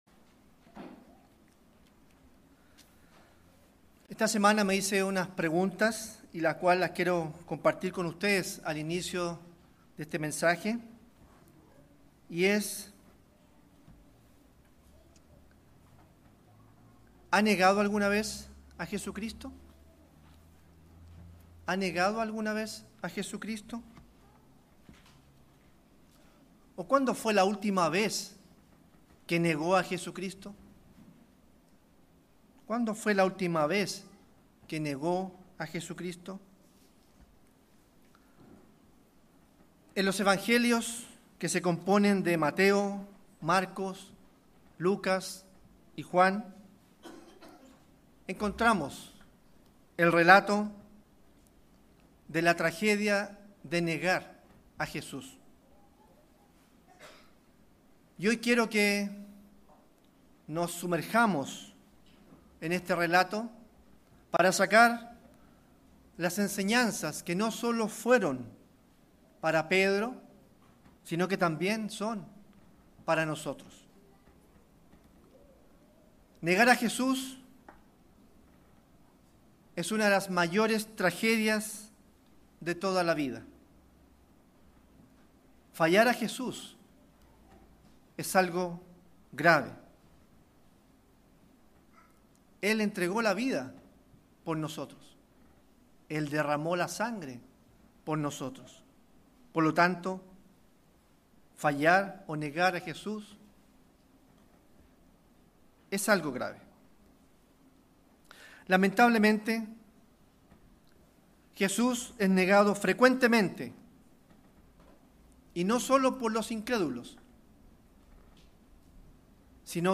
Given in Santiago